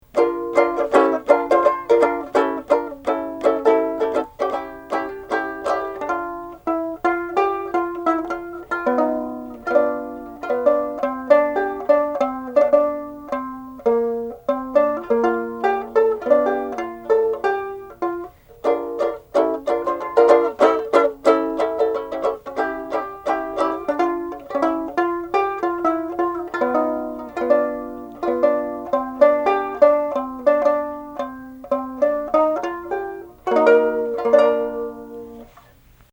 Here is a home-made banjo uke made from an 8 inch REMO Fiberskyn 3 hand drum that sells for under $20.00 together with some old ukulele and banjo parts.
The uke should be tuned F, Bb, D. G going from the 4th string to the 1st string and will sound good even if it is tuned a half step lower than this.